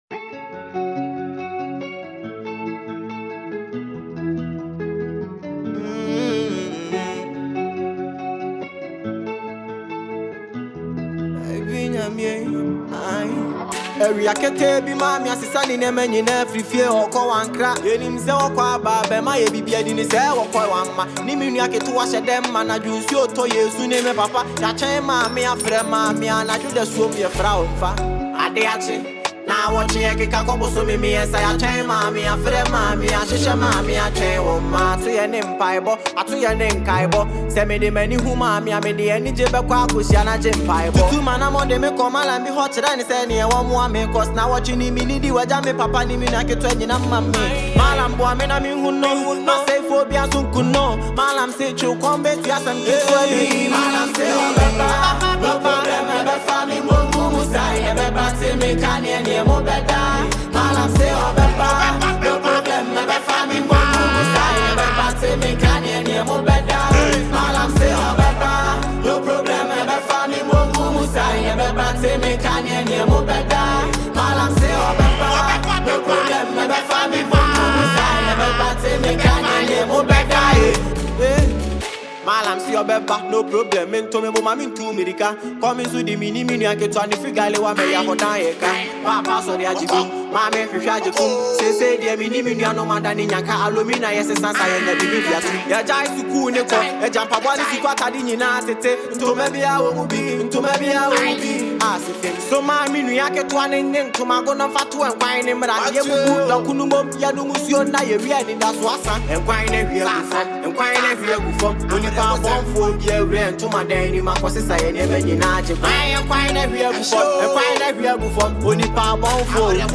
emotional new single